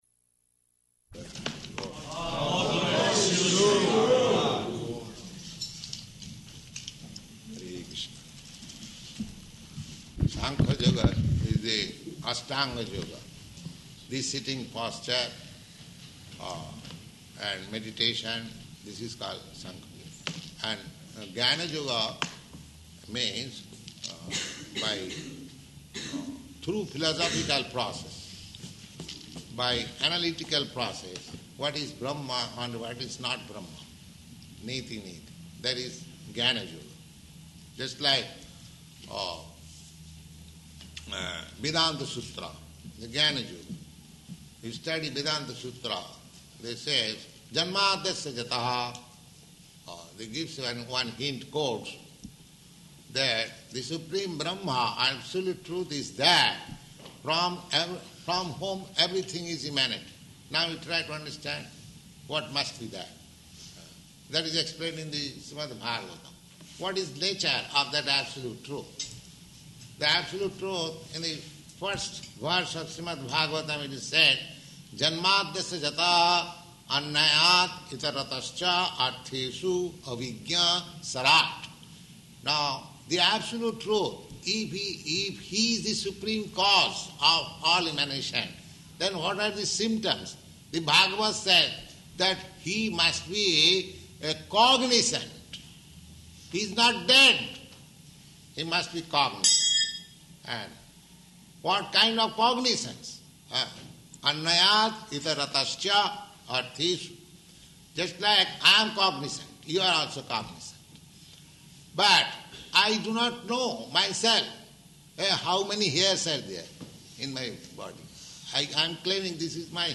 Bhagavad-gītā 6.13–15 [Sāṅkhya Yoga System] --:-- --:-- Type: Bhagavad-gita Dated: February 16th 1969 Location: Los Angeles Audio file: 690216BG-LOS_ANGELES.mp3 Devotees: All glories to Śrī guru, Śrīla Prabhupāda. [devotees offer obeisances] Prabhupāda: Hare Kṛṣṇa.